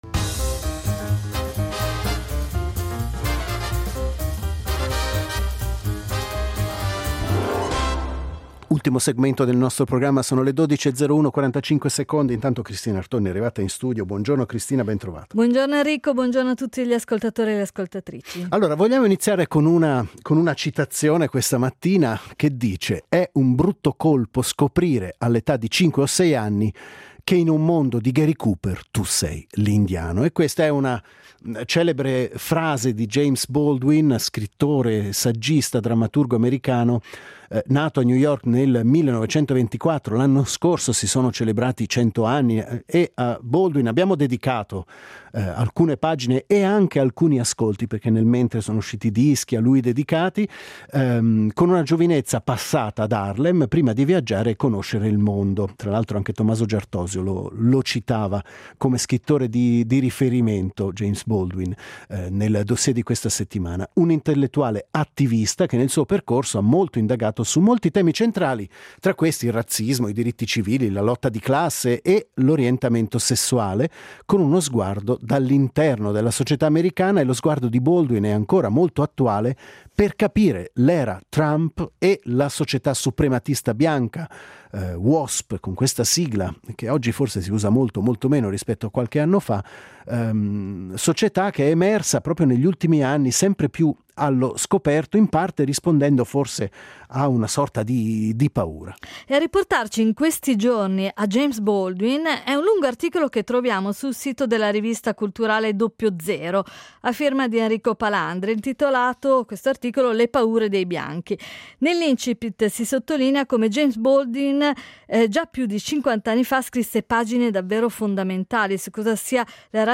In Alphaville ci siamo confrontati su questi temi con due ospiti